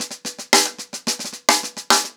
TheQuest-110BPM.5.wav